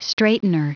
Prononciation du mot : straightener
straightener.wav